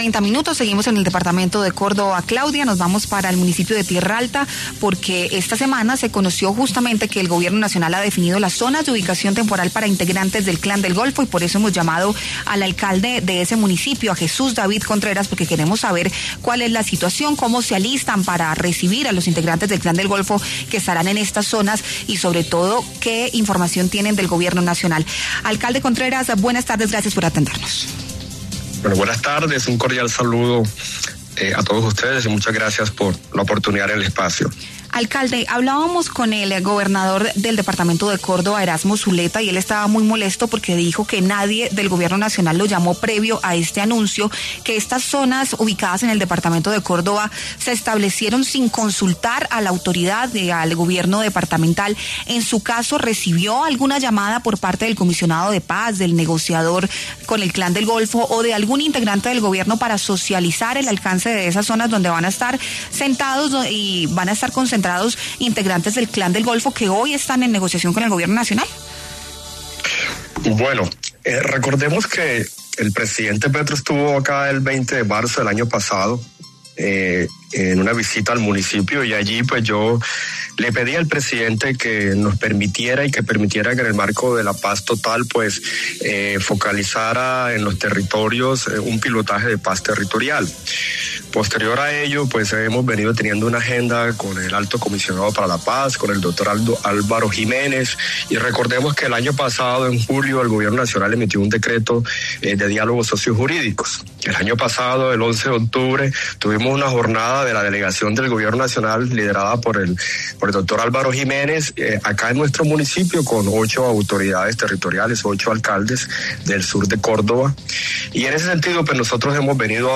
En diálogo con La W, el alcalde del municipio de Tierralta, Córdoba, Jesús David Contreras, habló sobre la expectativa en esta jurisdicción del Alto Sinú que fue escogida mediante resolución como Zona de Ubicación Temporal (ZUT) para miembros del Clan del Golfo que permitan la transición hacia la vida civil.